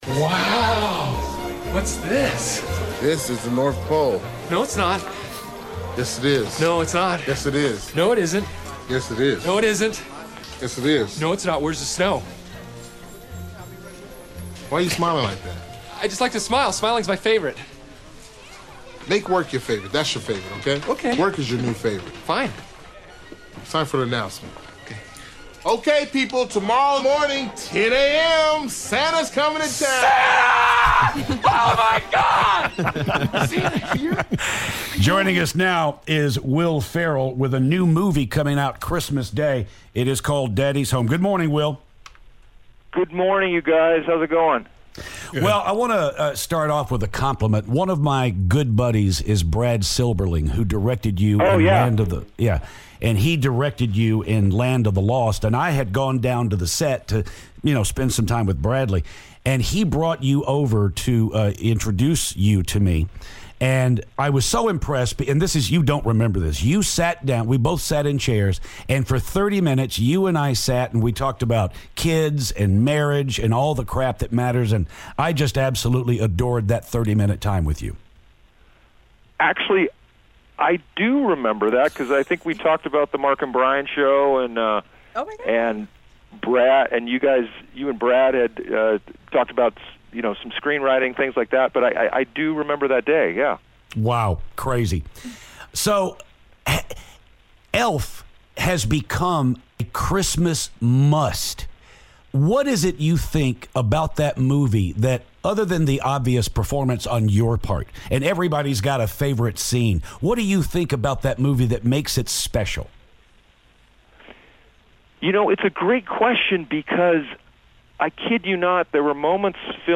Will Ferrell Phoner
Will Ferrell calls to talk about his new movie "Daddy's Home" with Mark Wahlberg.